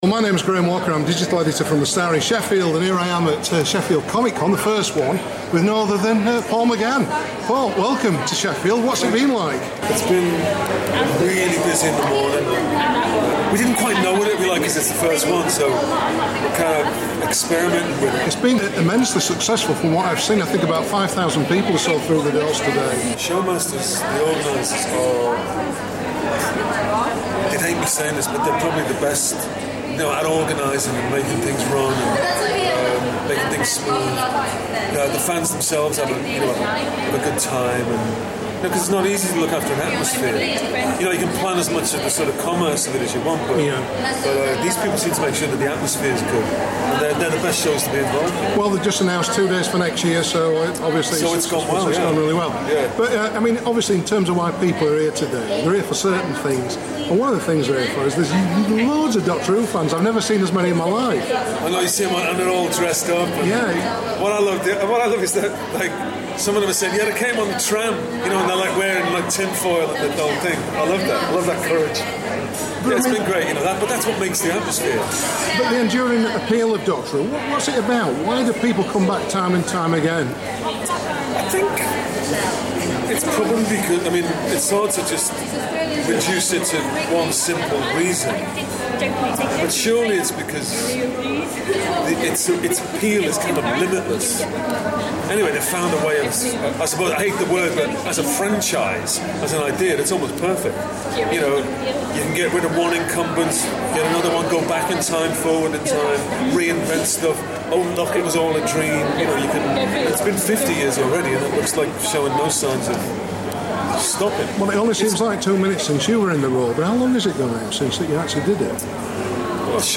Interview: Paul McGann
Doctor Who star Paul McGann tells us why he still loves the association with his role as the Time Lord. We chatted at SheffieldFilm and Comic Con 2014.